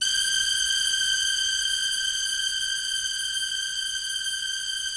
BIGORK.G5 -L.wav